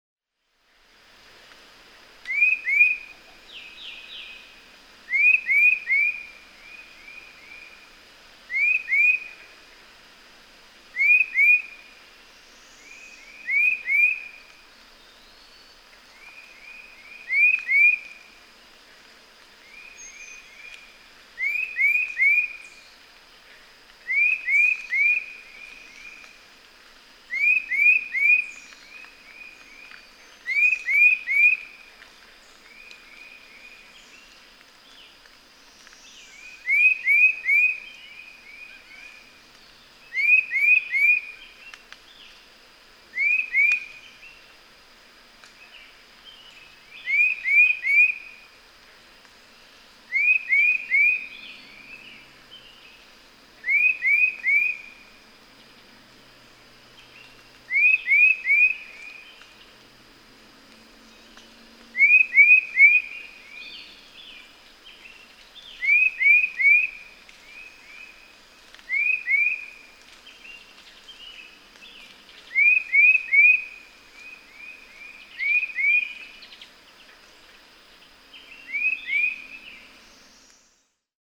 Tufted titmouse
Again, two different songs, but hear how, beginning at 0:05, the male in the background matches the male in the foreground.
Yorktown National Battlefield, Yorktown, Virginia.
392_Tufted_Titmouse.mp3